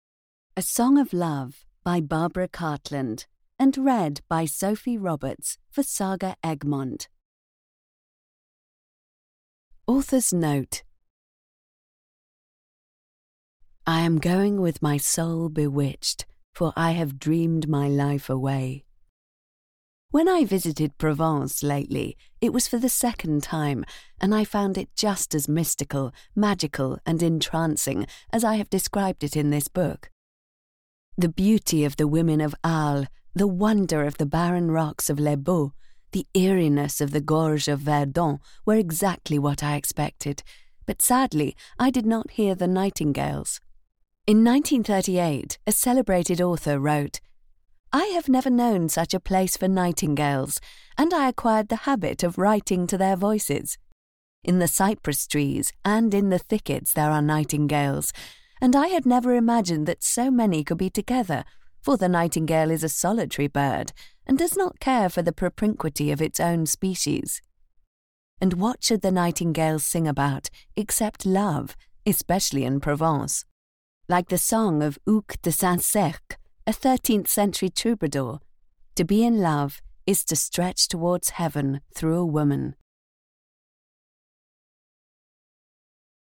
A Song of Love (EN) audiokniha
Ukázka z knihy